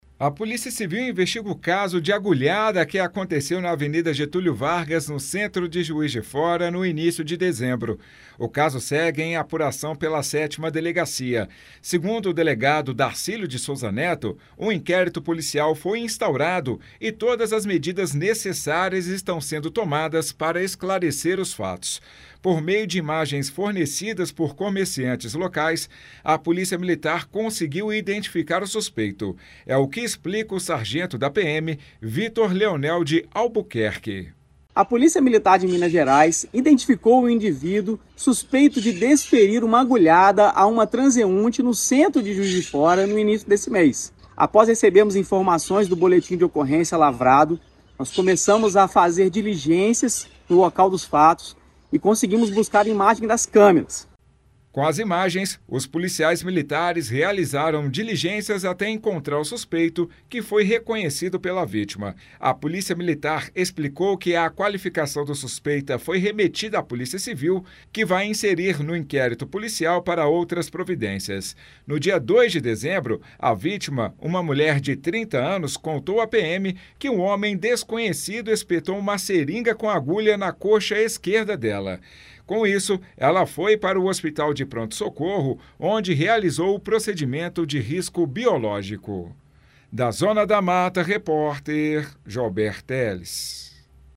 A PM explicou como as imagens fornecidas por comerciantes foram fundamentais para identificação do suspeito. Ouça na reportagem